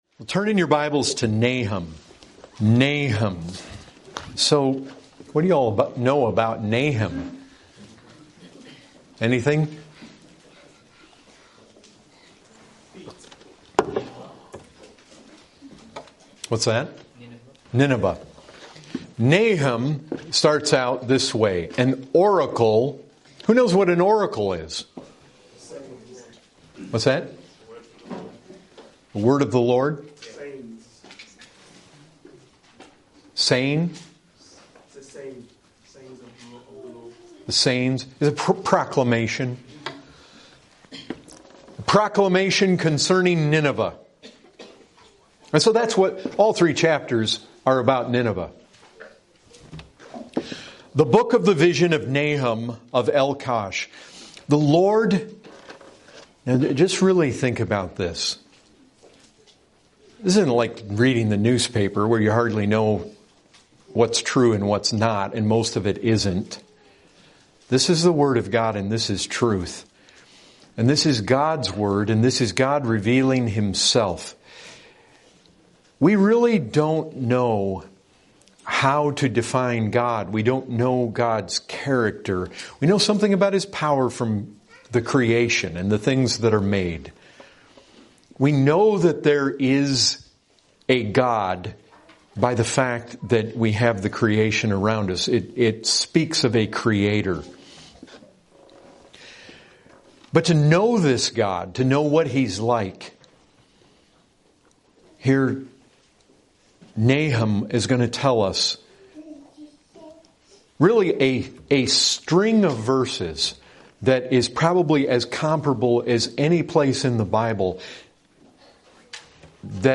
2022 Category: Full Sermons Topic